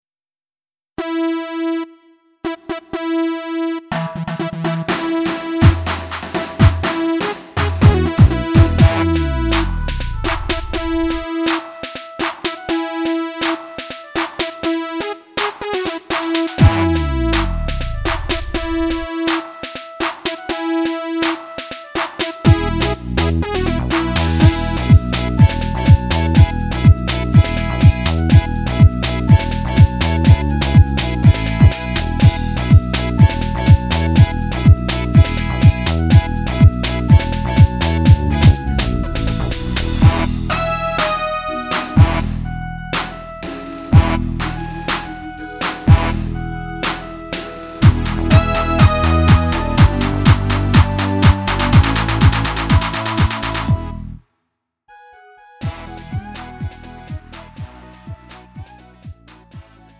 장르 가요
Lite MR